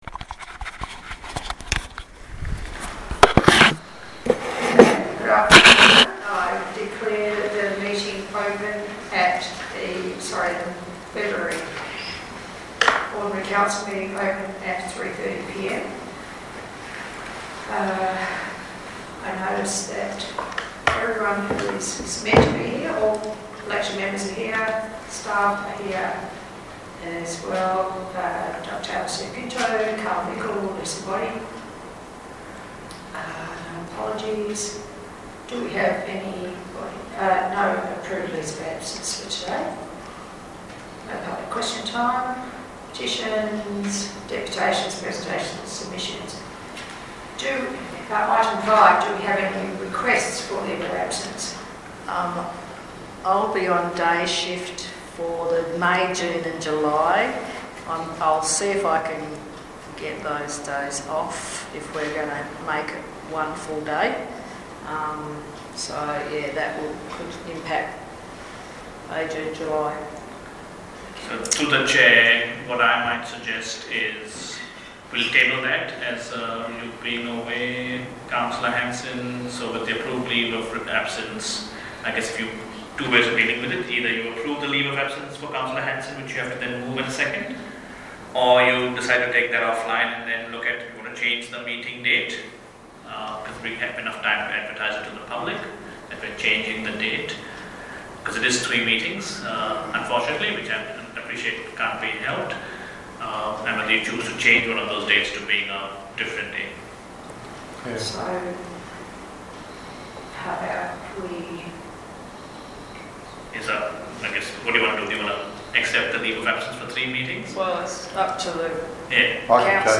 February 2026 Ordinary Council Meeting Recording (36.07 MB)